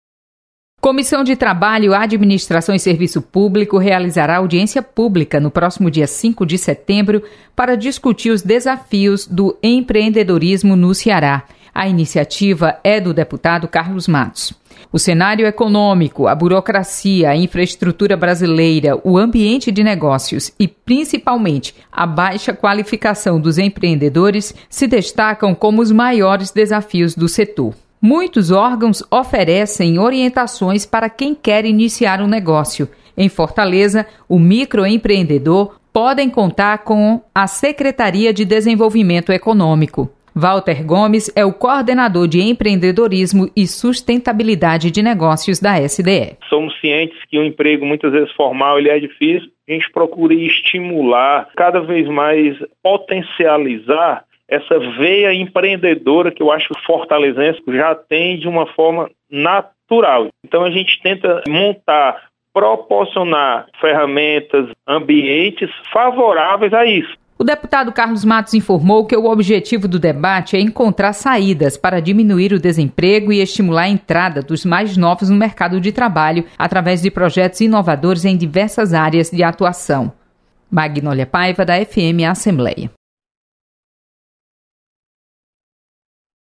Audiência vai buscar alternativas para empreendedores no Ceará. Repórter